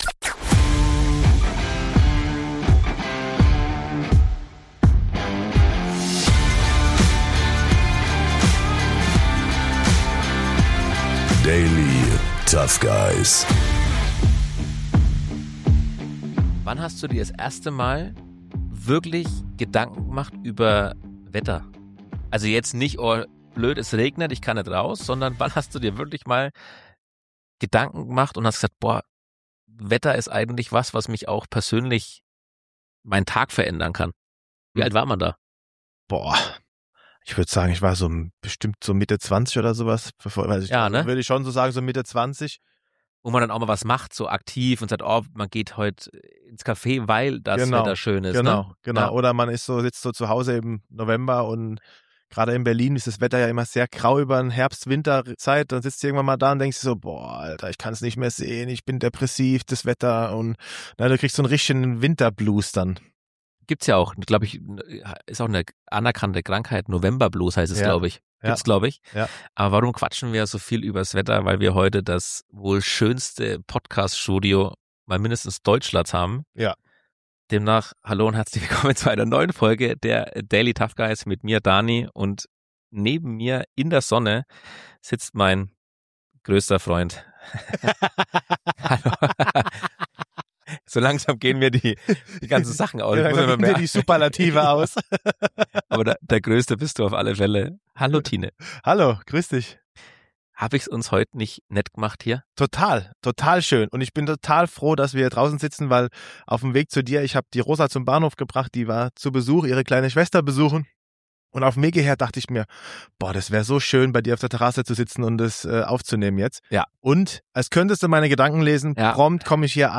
Für diese Folge haben wir das Studio gegen den Garten getauscht und in der Frühlingssonne aufgenommen. Dabei stellen wir uns eine Frage, die vermutlich jede Generation irgendwann beschäftigt: War früher eigentlich wirklich alles besser? Zwischen Vogelgezwitscher und Sonnenstrahlen sprechen wir über die heute normale Informationsflut im Internet, darüber, wie sich unsere Wahrnehmung von Nachrichten und Meinungen verändert hat und über Modesünden, die wir selbst durchlebt haben und heute kaum noch erklären können.